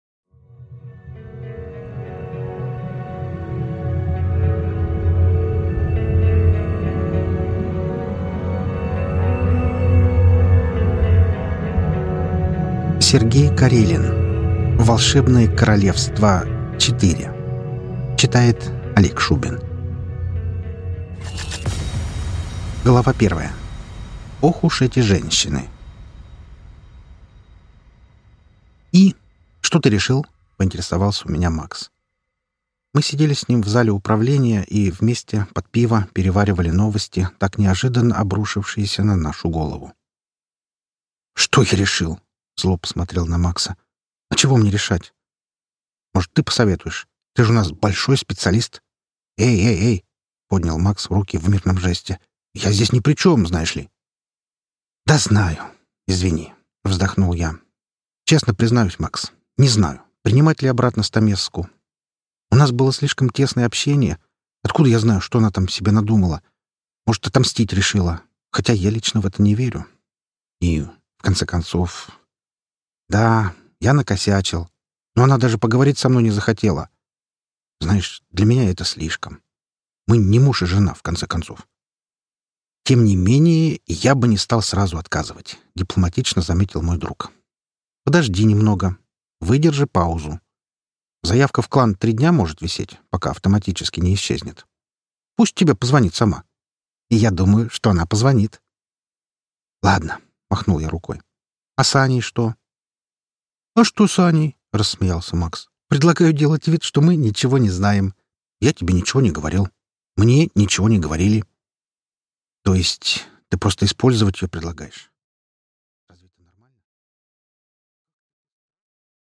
Аудиокнига Волшебные королевства 4 | Библиотека аудиокниг